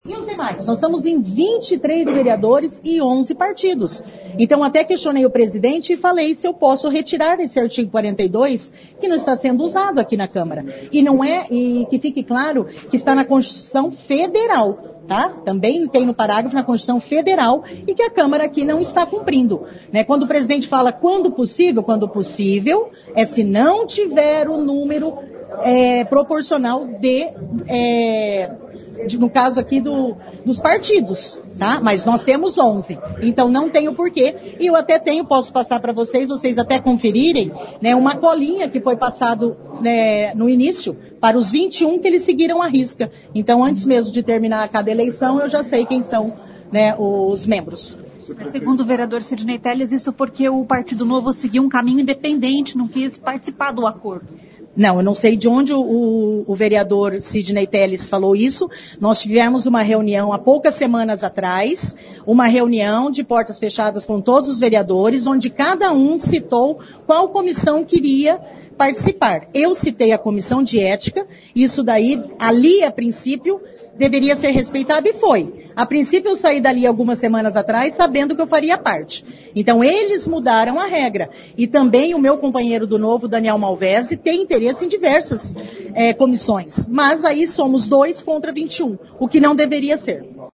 A vereadora Cris Lauer do Novo disse que a votação não seguiu o que havia sido decidido em reuniões anteriores e que os vereadores desrespeitaram o Regimento Interno da Câmara que prevê equilíbrio entre os partidos indicados para as comissões.